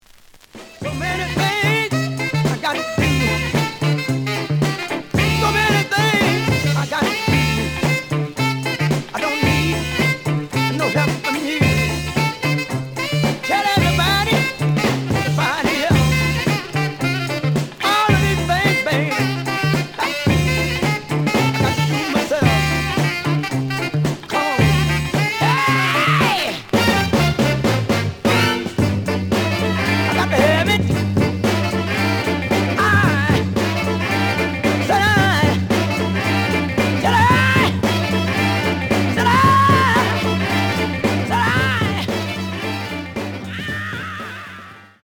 The audio sample is recorded from the actual item.
●Genre: Funk, 60's Funk
B side plays good.)